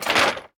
small_destroy3.ogg